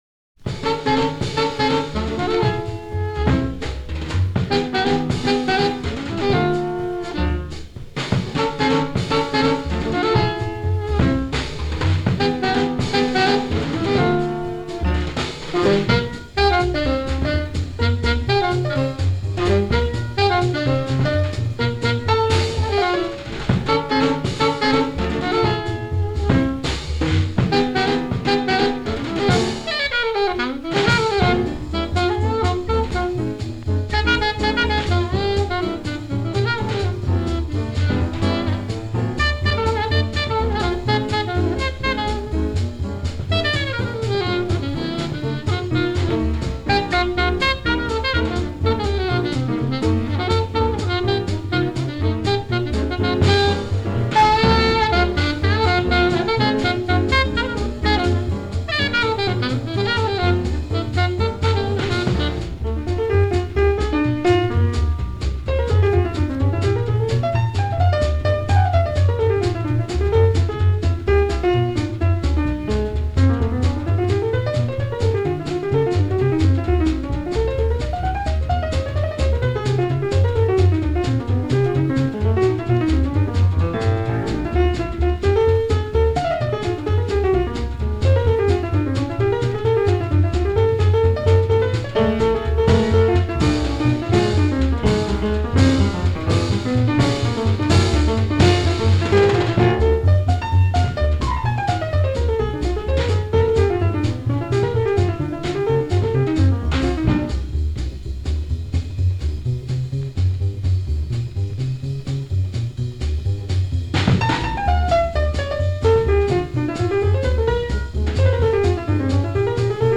Quartet
• MAINSTREAM (JAZZ)
• Alto Sax
• Piano
• Bass
• Drums